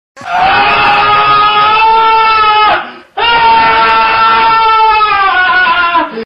4Chan meme Meme Effect sound effects free download